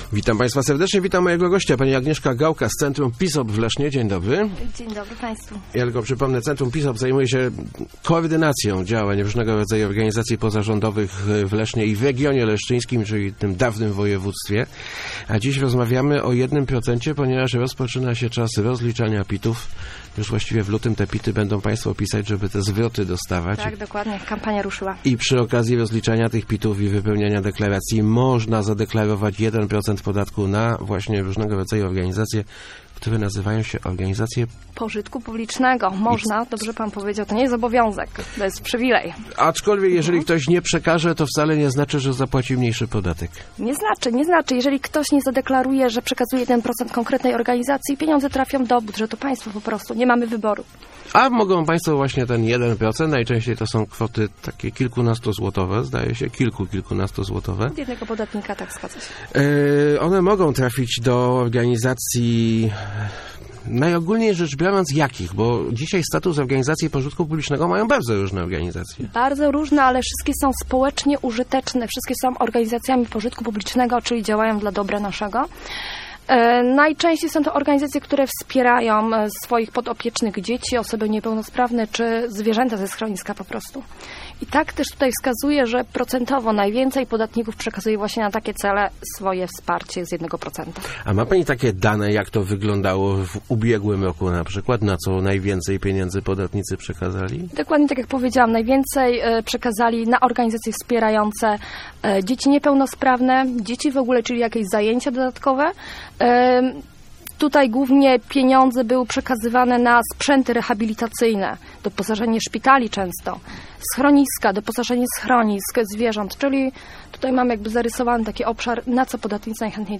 Start arrow Rozmowy Elki arrow Co z jednym procentem?